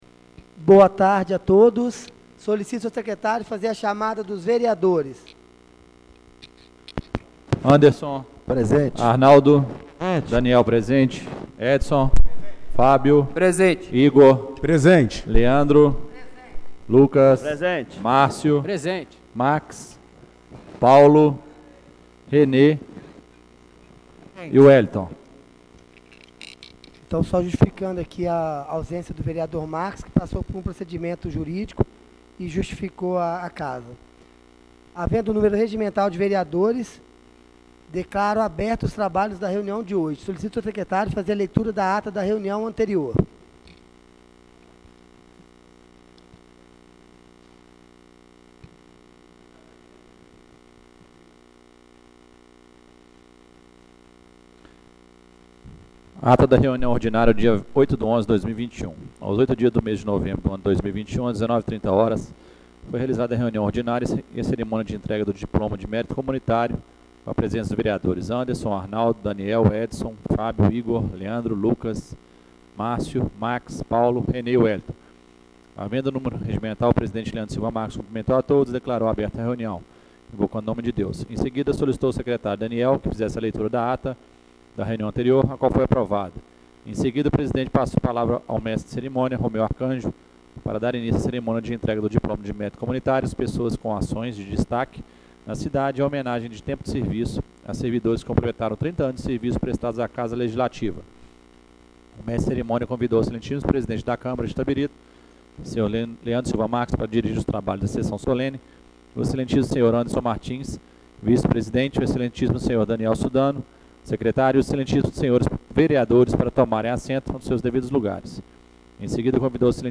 Reunião Extraordinária do dia 16/11/2021